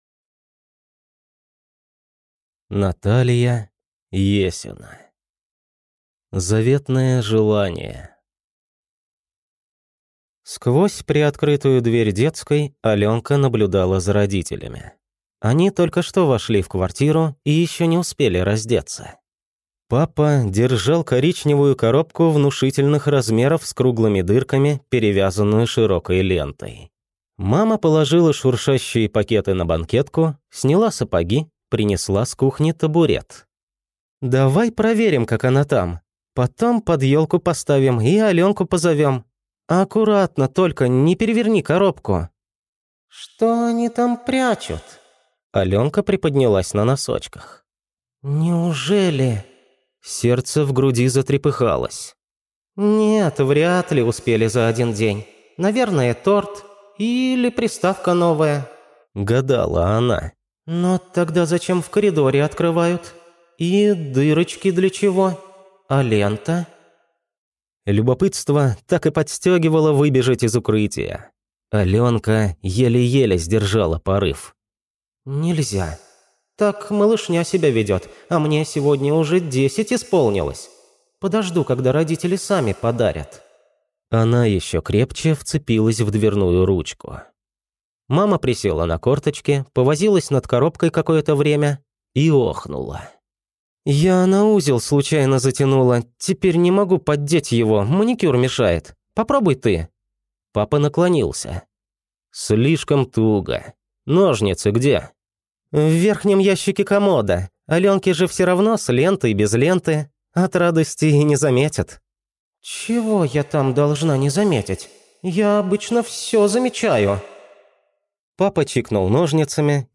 Аудиокнига Заветное желание | Библиотека аудиокниг